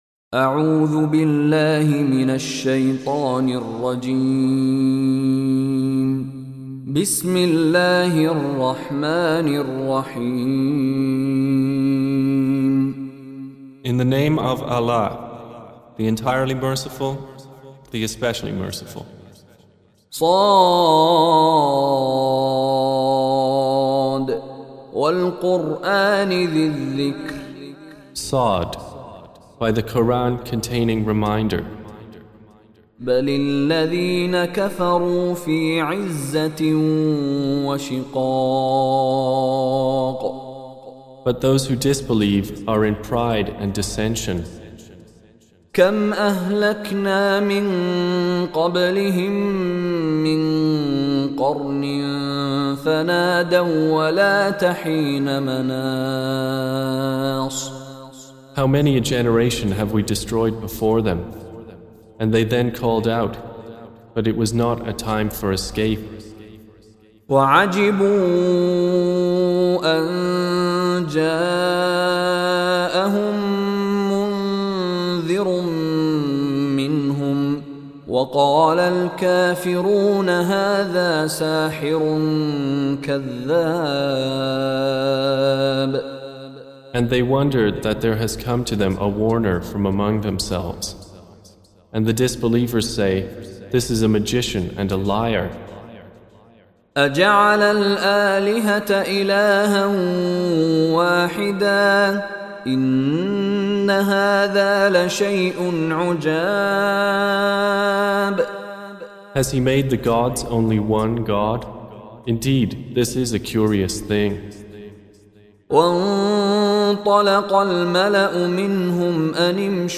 Audio Quran Tarjuman Translation Recitation
Surah Repeating تكرار السورة Download Surah حمّل السورة Reciting Mutarjamah Translation Audio for 38. Surah S�d. سورة ص N.B *Surah Includes Al-Basmalah Reciters Sequents تتابع التلاوات Reciters Repeats تكرار التلاوات